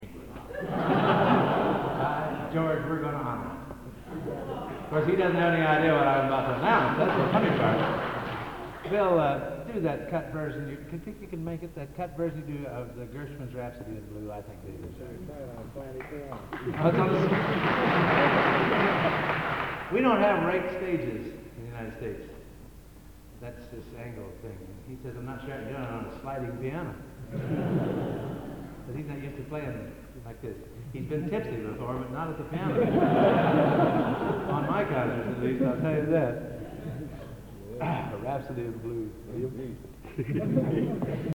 Location: Plymouth, England
Genre: | Type: Director intros, emceeing